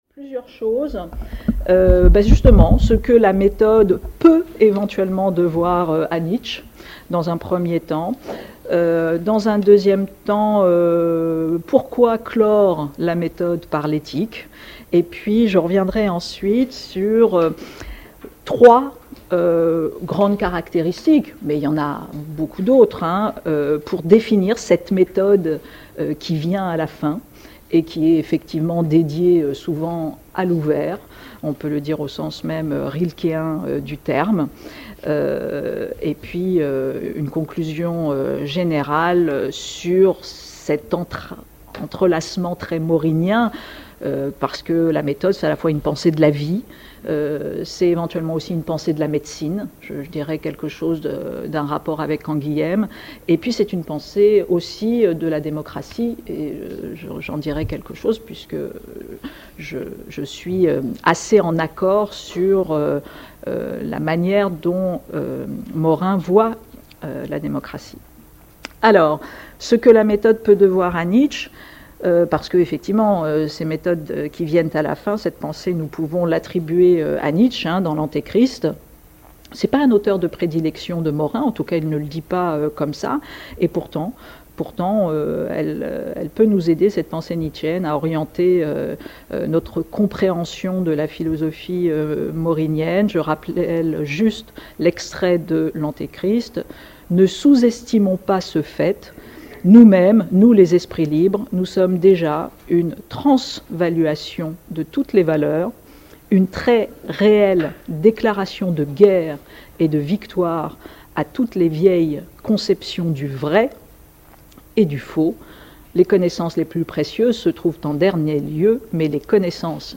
Cette conférence analyse ce que la "Méthode" peut devoir à Nietzsche et qu’est-ce que signifie "clore" la Méthode par l’éthique ?